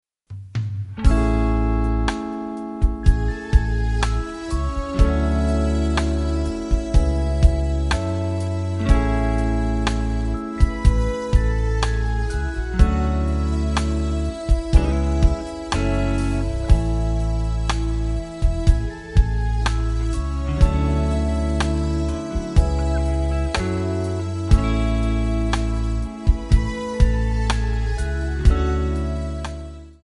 Backing track files: Duets (309)